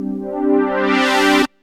SYNTH GENERAL-1 0003.wav